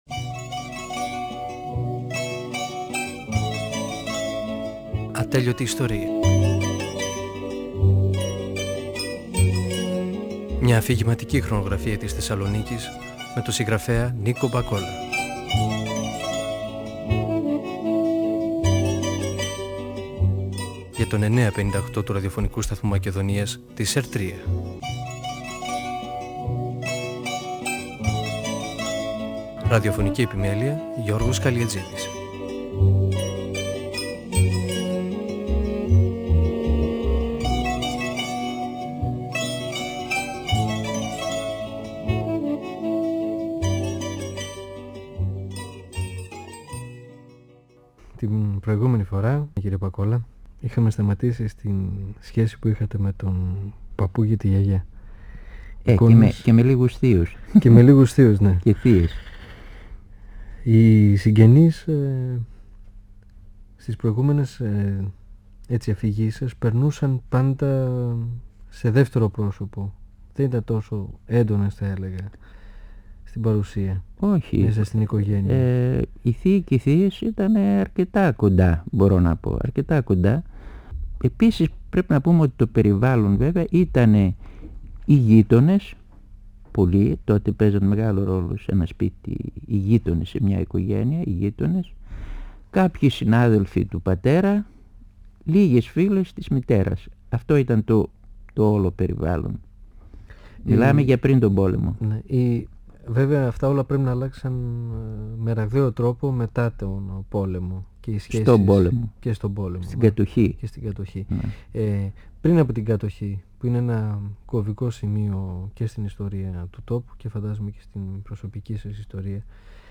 Ο πεζογράφος Νίκος Μπακόλας (1927-1999) μιλά για τα παιδικά του χρόνια στη γειτονιά του, την περιοχή της οδού 25ης Μαρτίου.